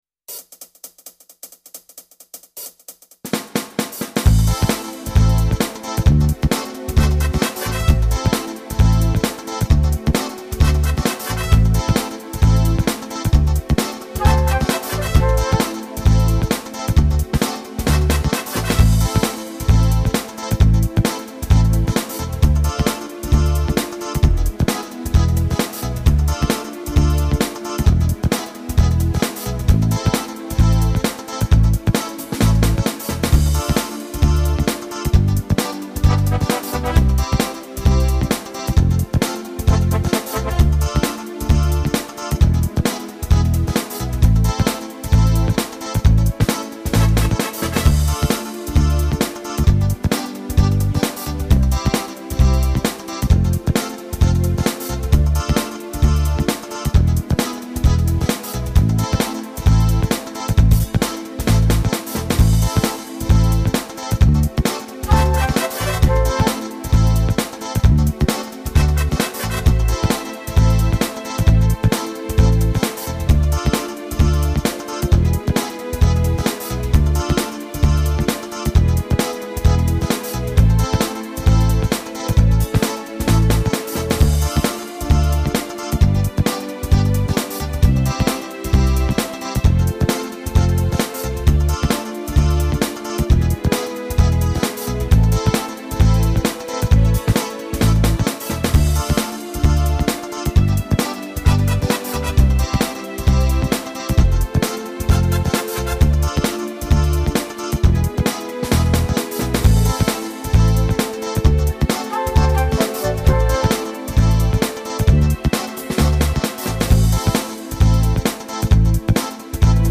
Слушать или скачать минус к песне